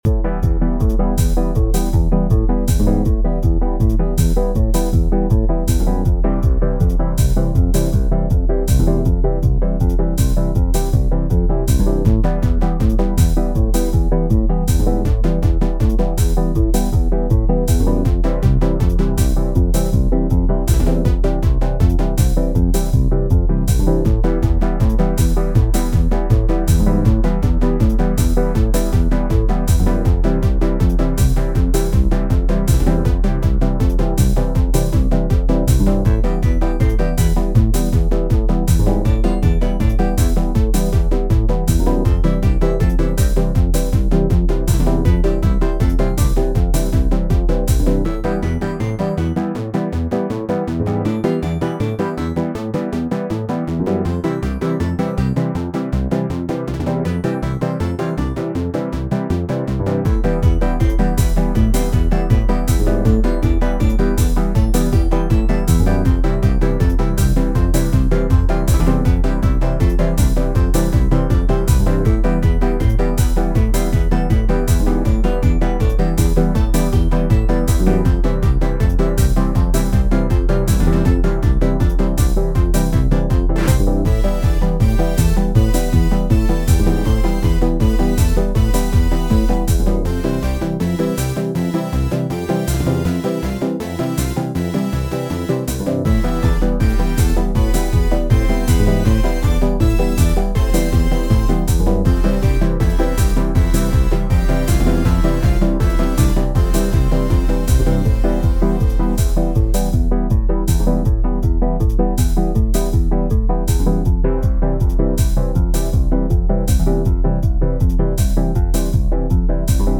A video-game-y music track I've been working on